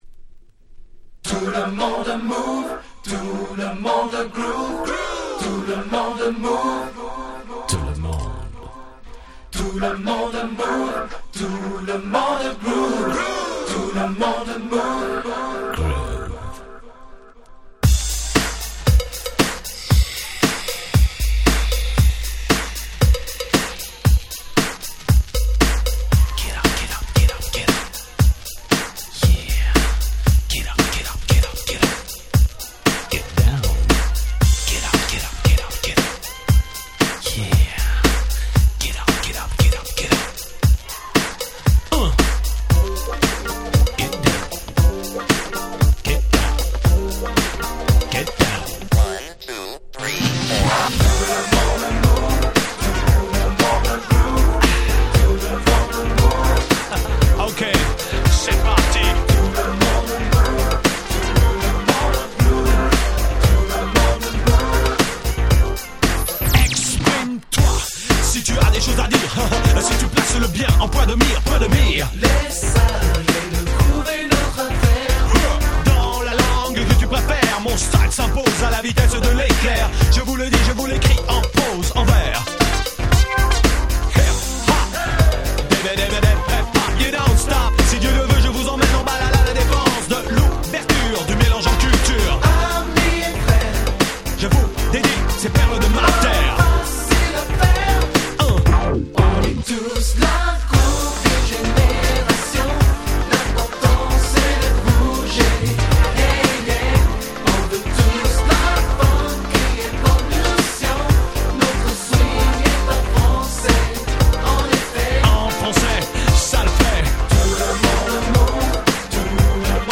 96' Nice French Rap / R&B !!
フレンチラップ 90's キャッチー系